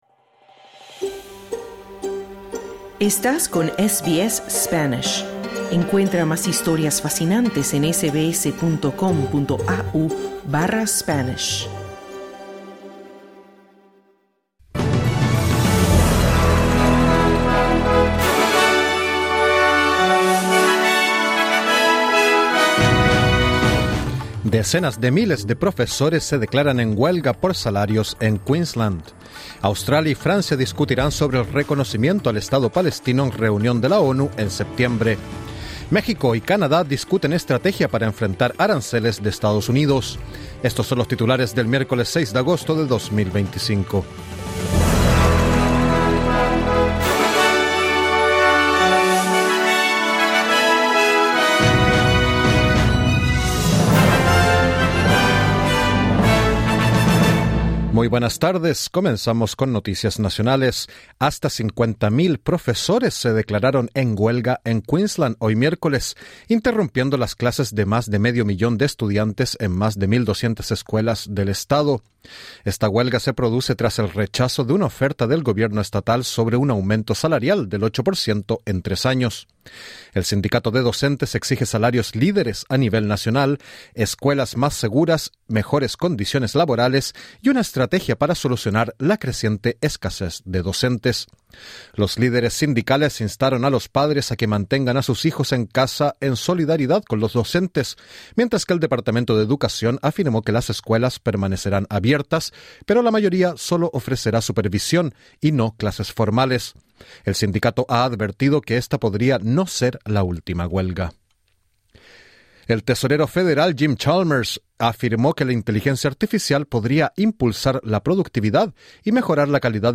Noticias SBS Spanish | 6 agosto 2025